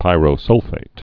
(pīrō-sŭlfāt)